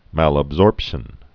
(măləb-zôrpshən, -sôrp-)